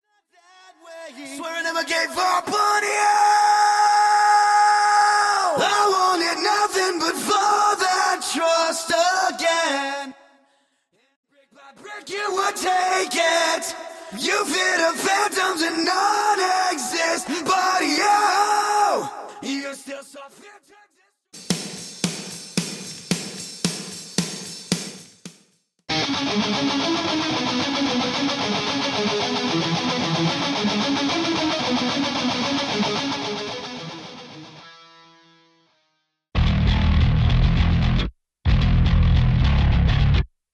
Studio Backing Vocals & Guitar Stem
Studio Bassline Guitar Stem
Studio Cymbals Stem
Studio Kick Stem
Studio Snare Stem